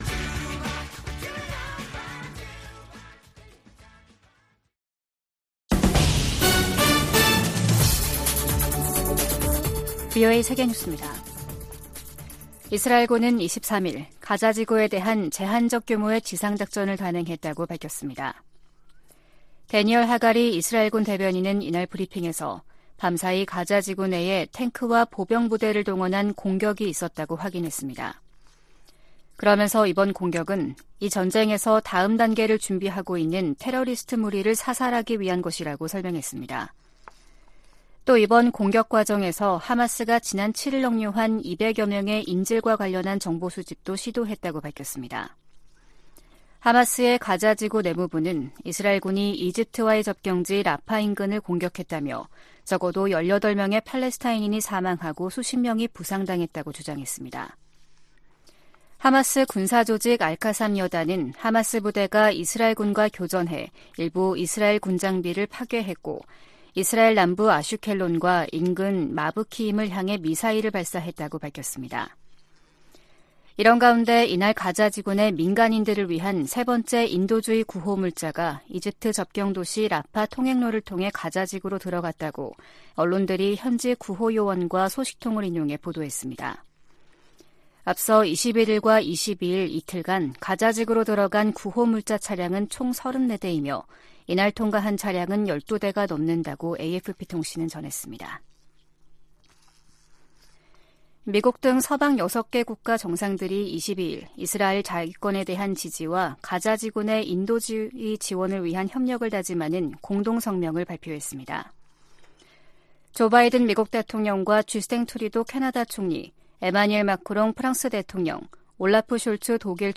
VOA 한국어 아침 뉴스 프로그램 '워싱턴 뉴스 광장' 2023년 10월 24일 방송입니다. 미국 백악관은 북한에서 군사 장비를 조달하려는 러시아의 시도를 계속 식별하고 폭로할 것이라고 강조했습니다. 미국 정부가 북한과 러시아의 무기 거래 현장으로 지목한 라진항에 또다시 컨테이너 더미가 자리했습니다.